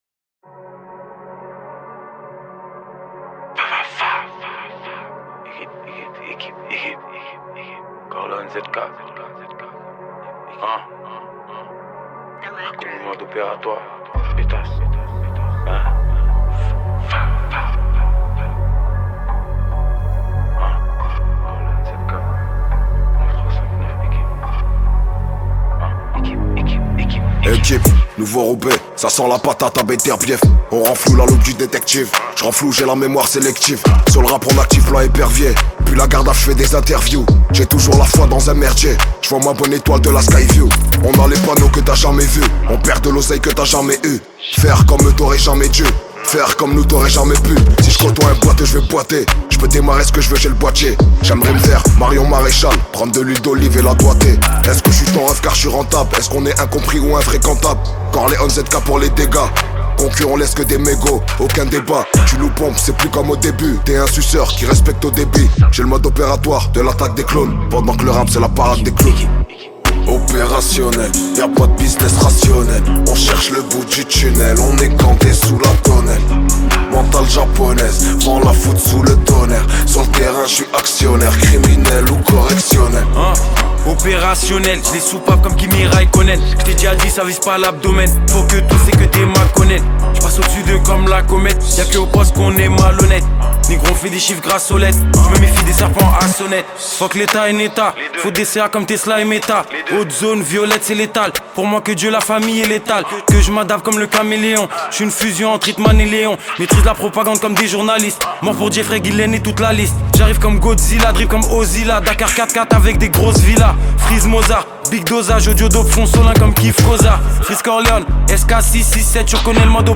43/100 Genres : french rap Télécharger